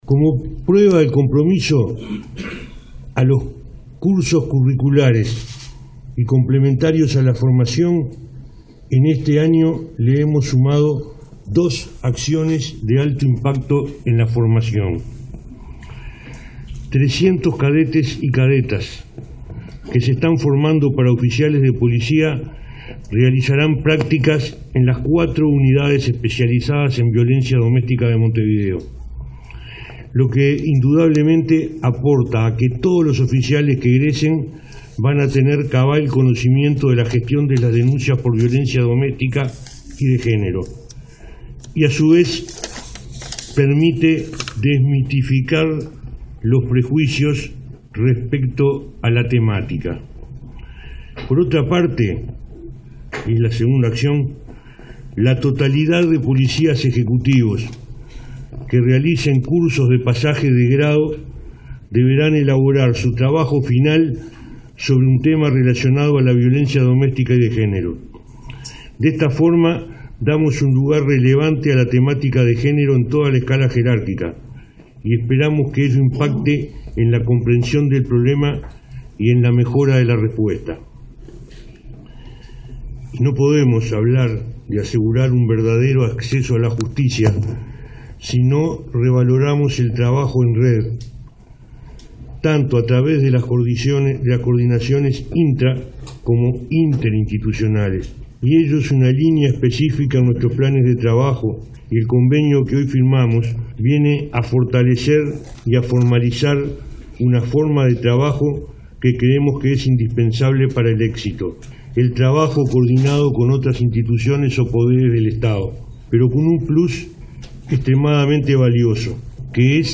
300 cadetes que se forman para oficiales de policía realizarán prácticas en unidades especializadas en violencia en Montevideo. A su vez, policías que cursen pasaje de grado deberán elaborar el trabajo final sobre género, dijo el ministro del Interior, Eduardo Bonomi, en la firma del acuerdo con el Poder Judicial, Fiscalía General de la Nación y la Red de Violencia Doméstica y Sexual para instalar un comité de trabajo.